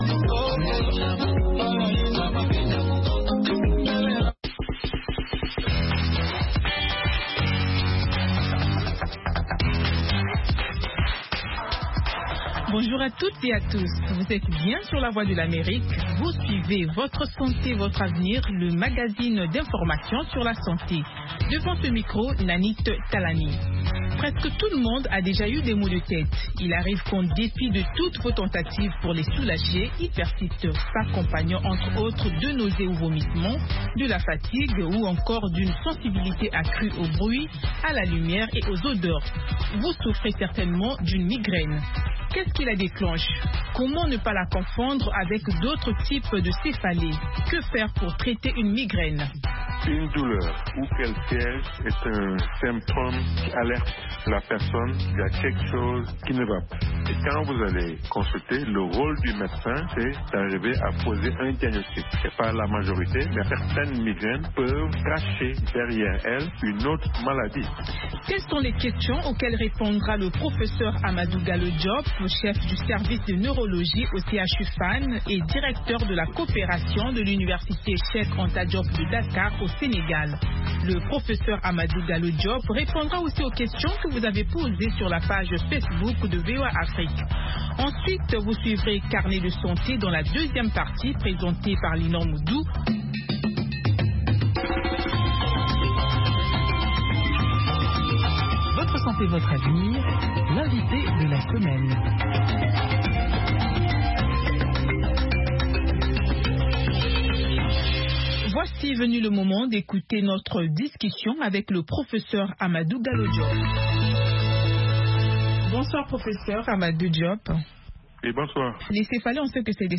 Fistules etc. Avec les reportages de nos correspondants en Afrique. VOA donne la parole aux personnes affectées, aux médecins, aux expert, aux parents de personnes atteintes ainsi qu’aux auditeurs.